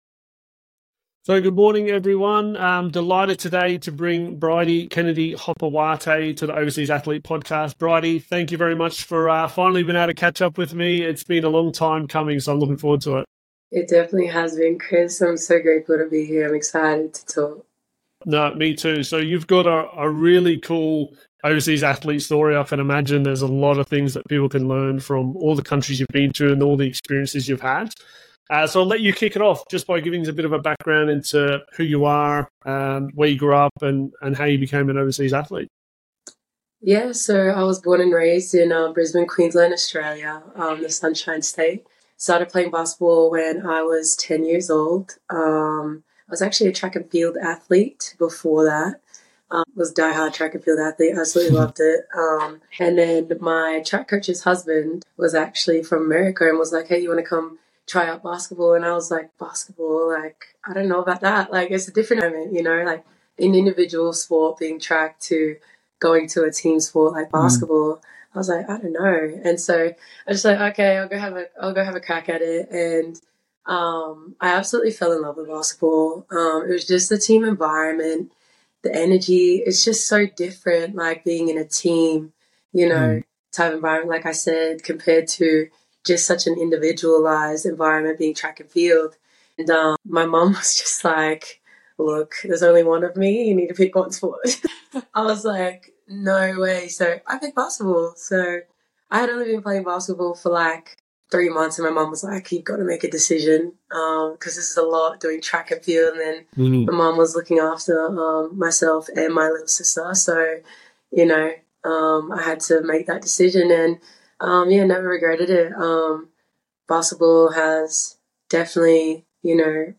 Real conversations with the specialists and athletes who know what it actually takes to build a career overseas.